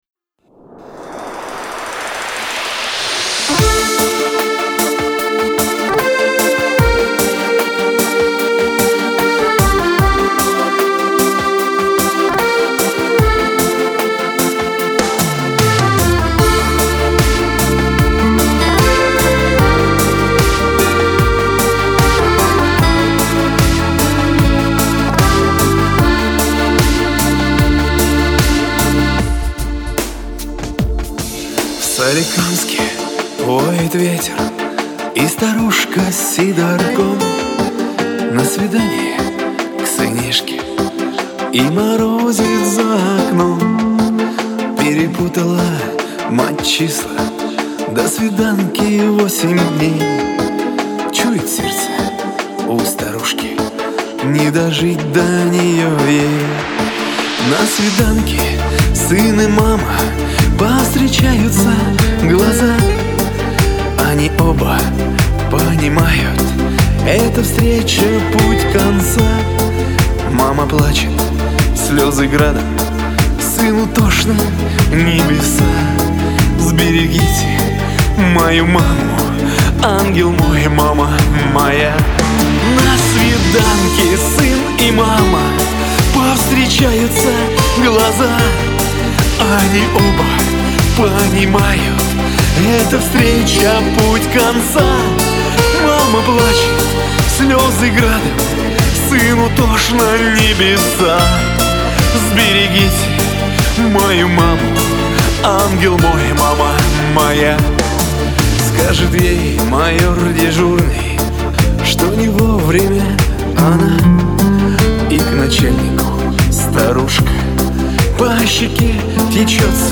Жанр: Chanson